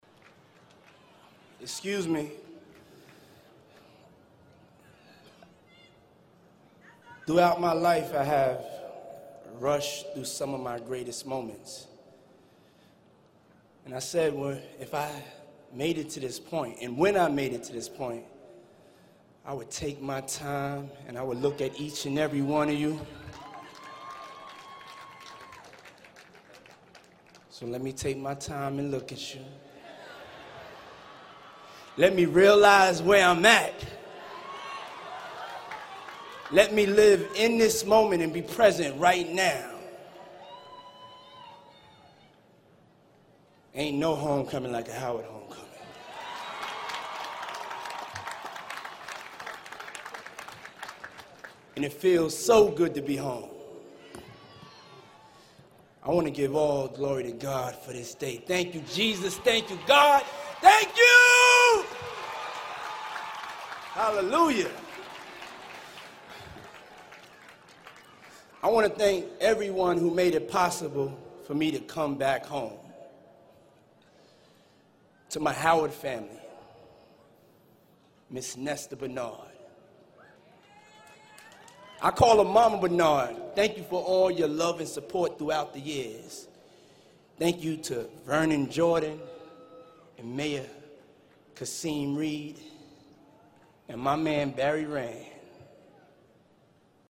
公众人物毕业演讲 第46期:肖恩库姆斯霍华德大学(2) 听力文件下载—在线英语听力室